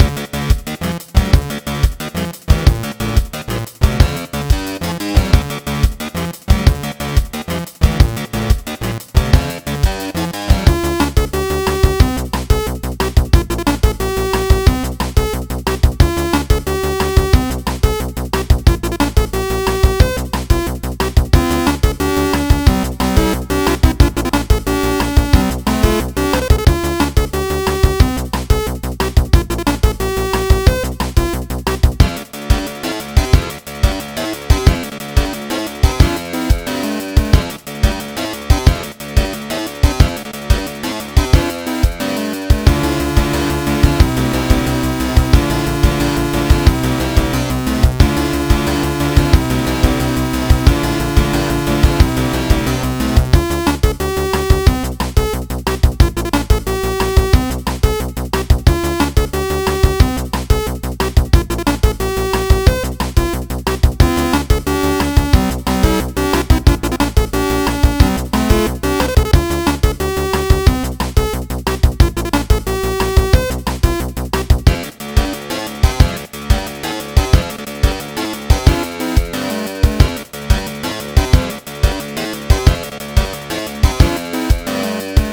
A small upbeat/racing theme